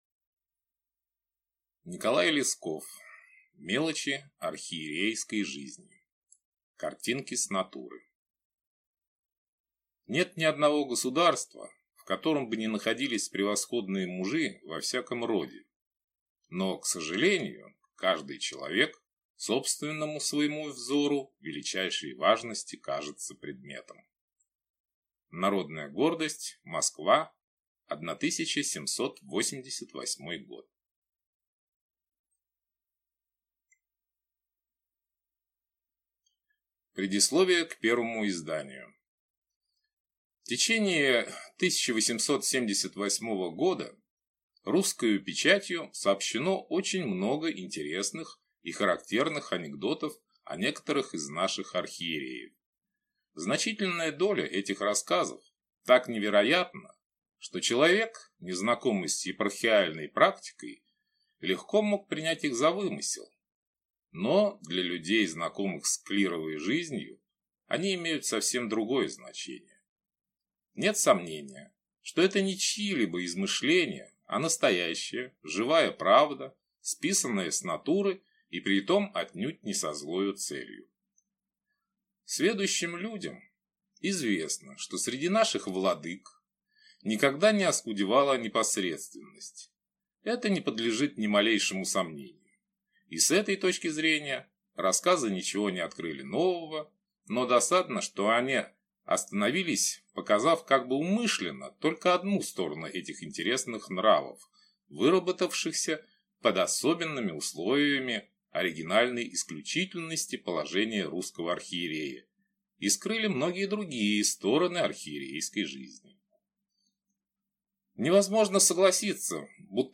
Аудиокнига Мелочи архиерейской жизни | Библиотека аудиокниг